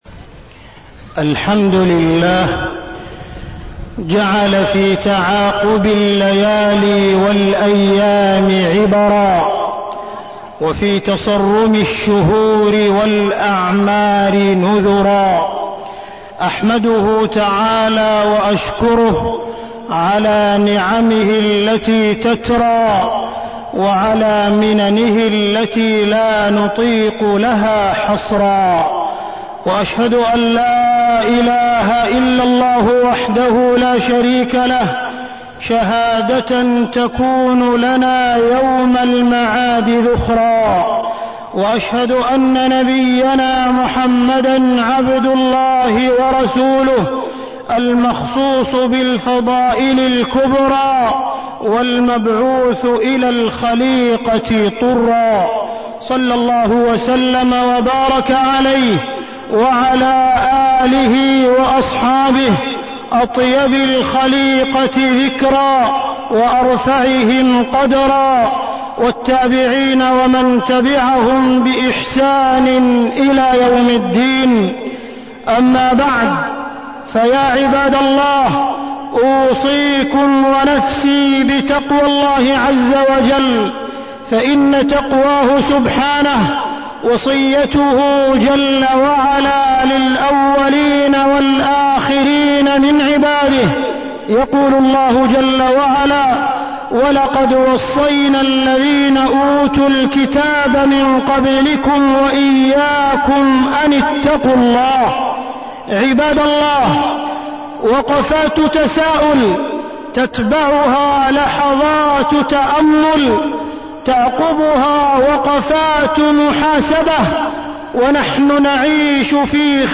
تاريخ النشر ٣٠ ذو الحجة ١٤١٦ هـ المكان: المسجد الحرام الشيخ: معالي الشيخ أ.د. عبدالرحمن بن عبدالعزيز السديس معالي الشيخ أ.د. عبدالرحمن بن عبدالعزيز السديس الدنيا الفانية